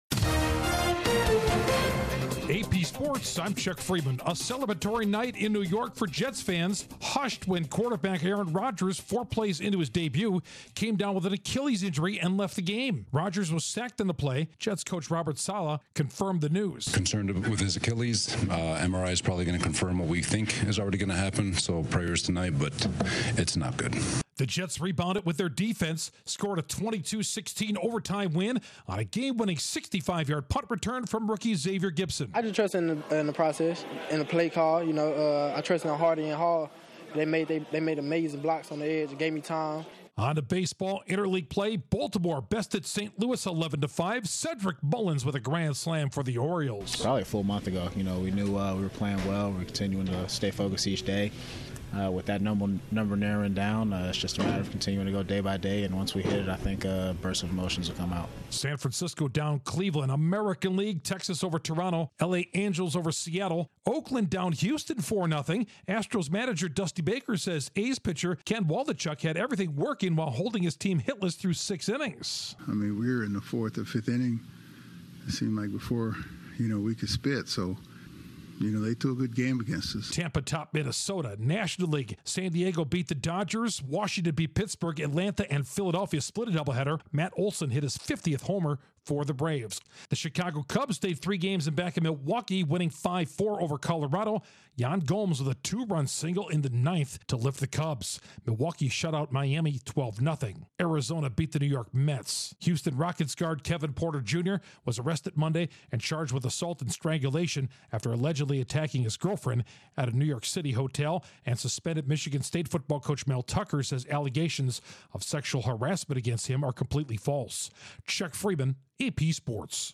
The Jets lose Aaron Rodgers but rebound to win, the Braves' Matt Olson hits his 50th homer, the Cubs rally to stay 3 behind the Brewers and Rockets guard Kevin Porter junior is arrested. Correspondent